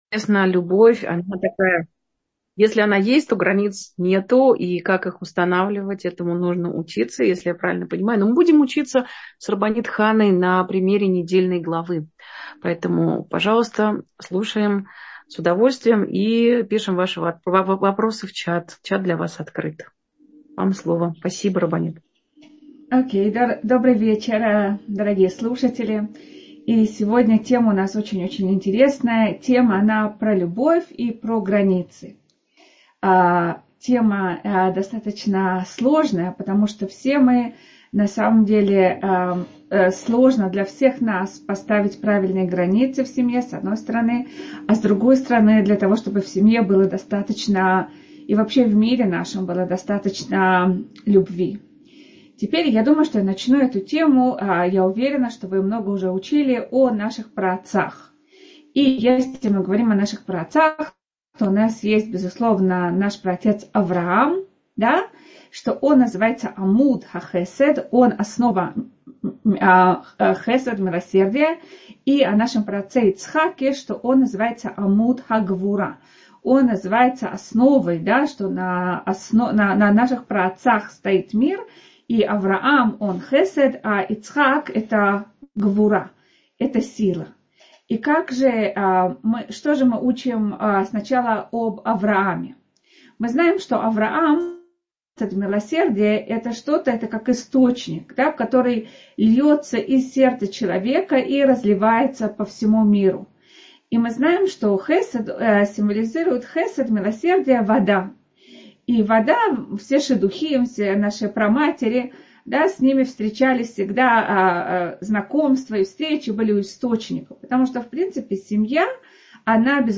О любви и границах — слушать лекции раввинов онлайн | Еврейские аудиоуроки по теме «Еврейская семья» на Толдот.ру